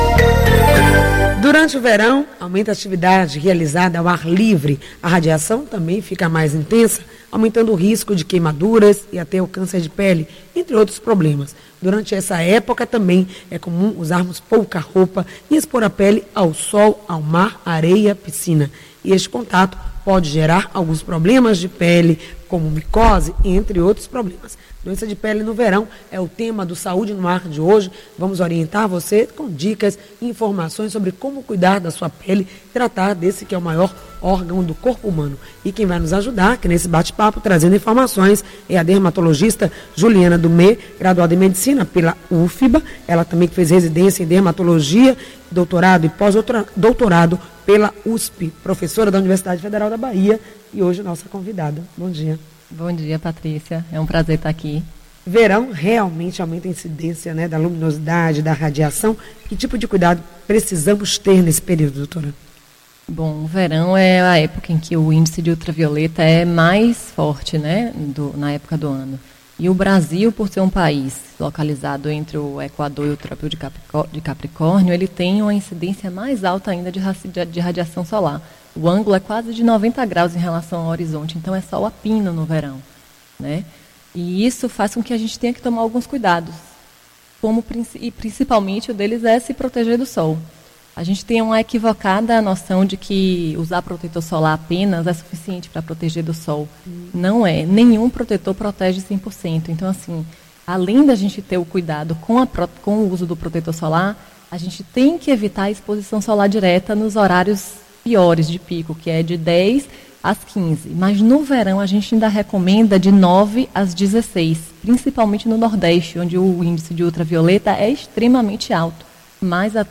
Com a chegada do verão aumenta o fluxo de pessoas em praias, piscinas, rios e lagos realizando suas atividades ao ar livre. Durante essa época do ano, a radiação fica mais intensa e as pessoas ficam vulneráveis as diversas doenças, correndo o risco de queimaduras, câncer da pele, dentre outros problemas similares. <br Ouça na íntegra a entrevista com a dermatologista